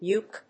ユーキュー